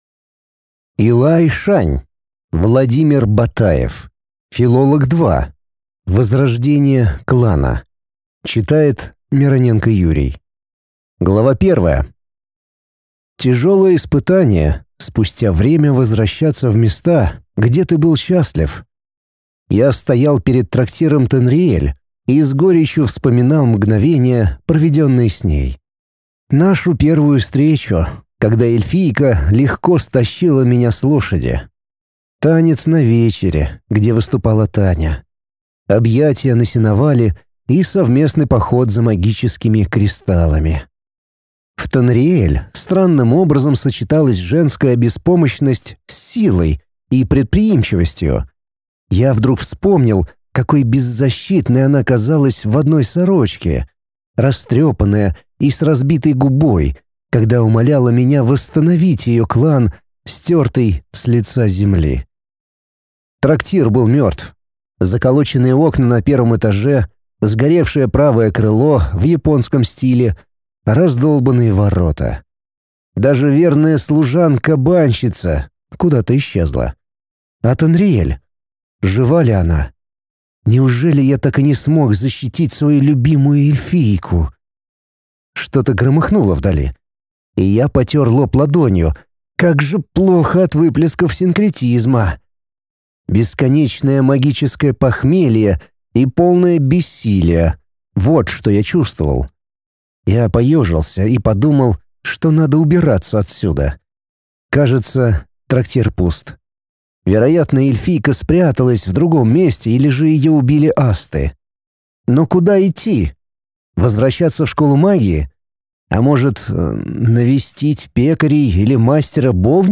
Аудиокнига Филолог 2. Возрождение клана | Библиотека аудиокниг